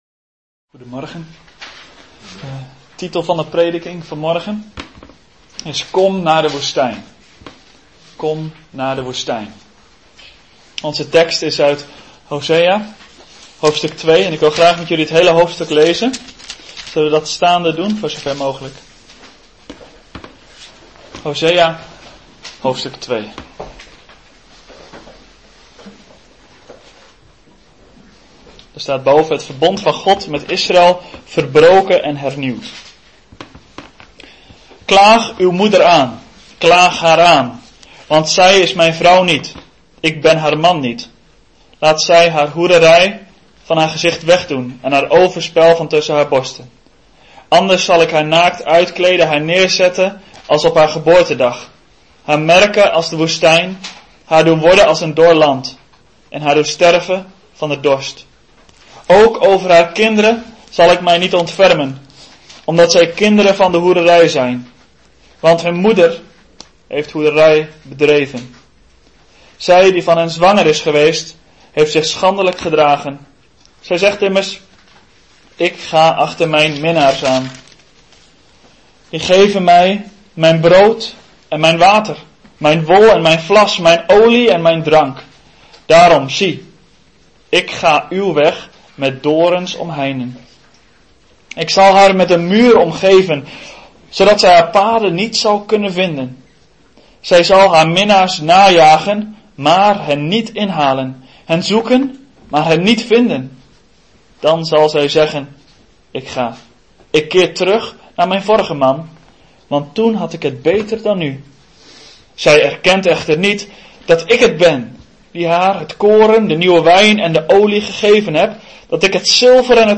Een preek over 'Kom naar de woestijn!'.